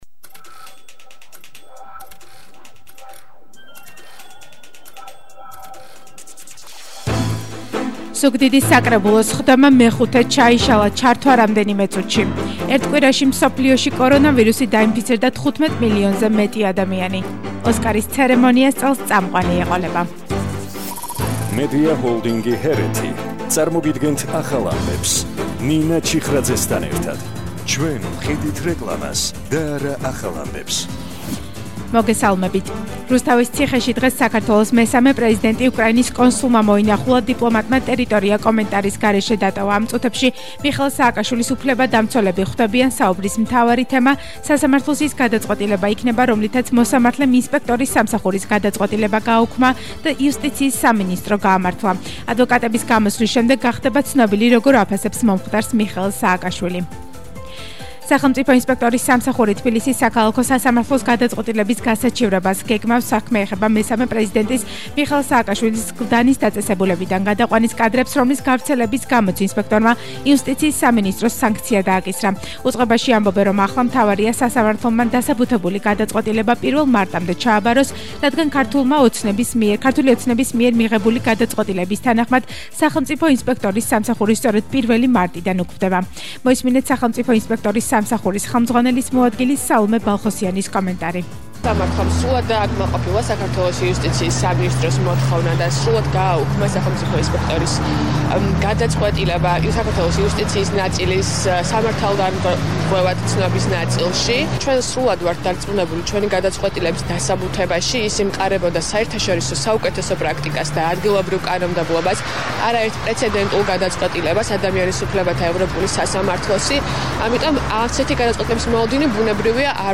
ახალი ამბები 14:00 საათზე – 12/01/22 – HeretiFM